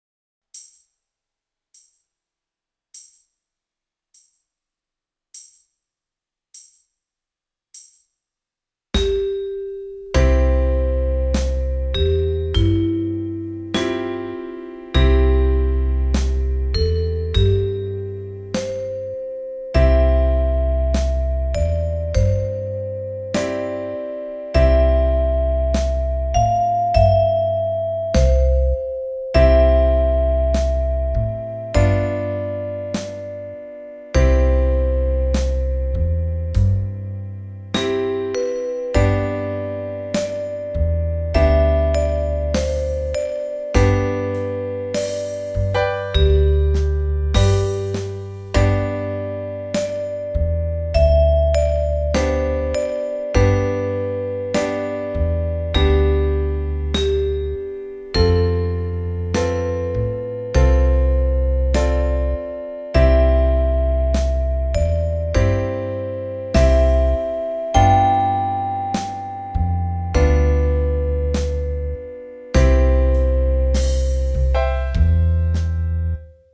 Bluesharp